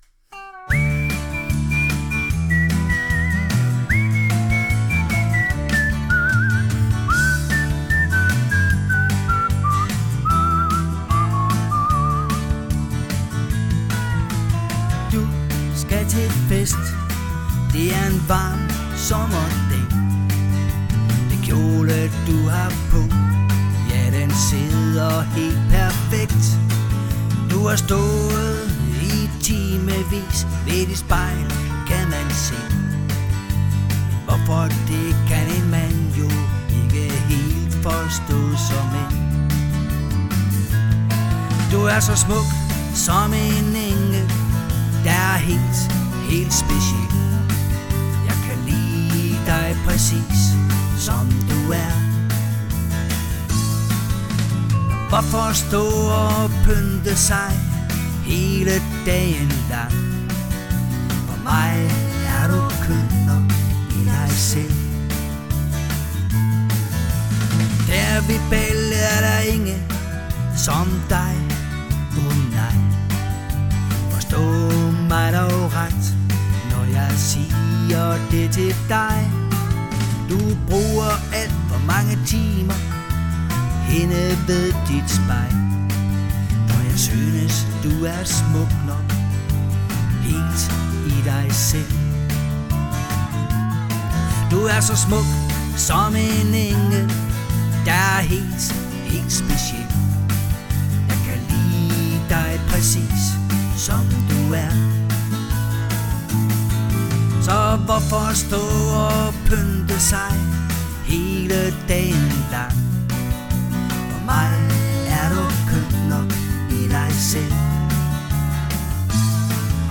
Er en duo ,Vi spille til stort set til alle typer af fester.
• Dansk top musik